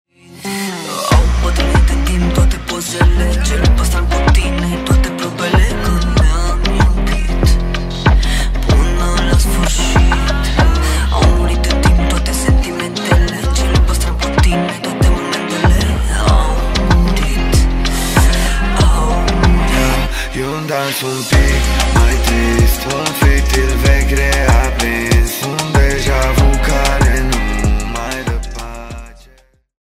Categorie: Manele